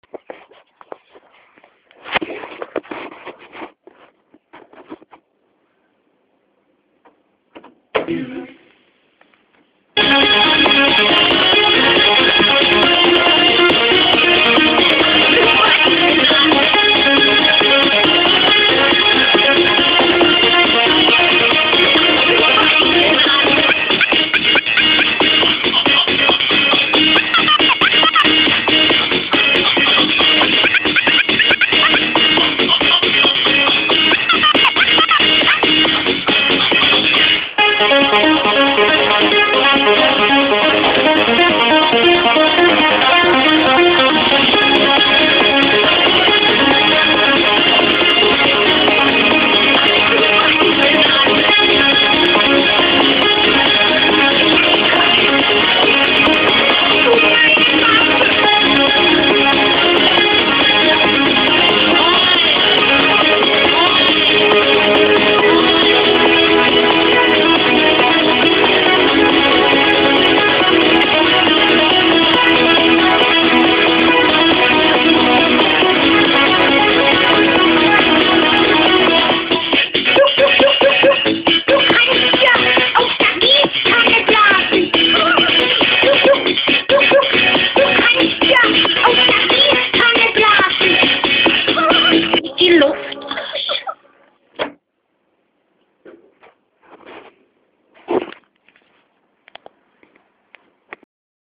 Прошу опознать Euro Dance.